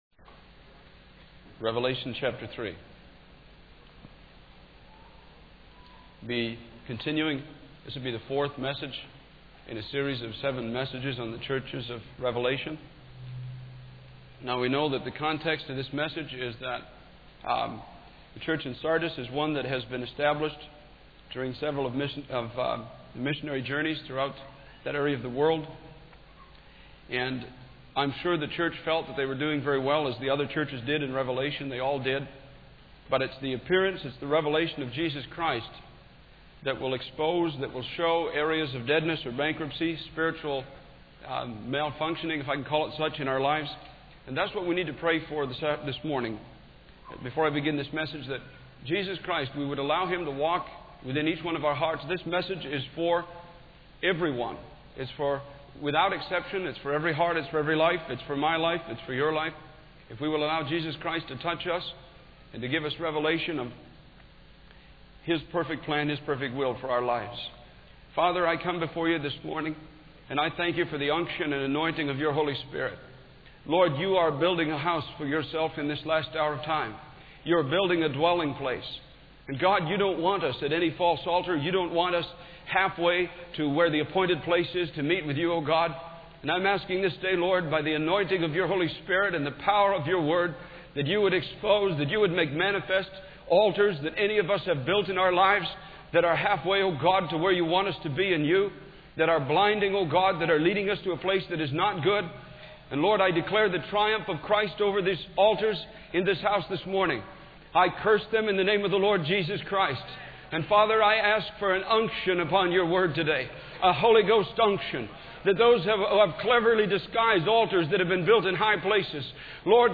In this sermon, the preacher emphasizes the importance of meeting with God in the appointed place. He encourages the congregation to surrender everything they have and are to God, acknowledging that everything belongs to Him.